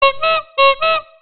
Ringetone Horn
Kategori Lydeffekt